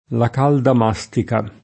mastica [ ma S t & ka o m #S tika ]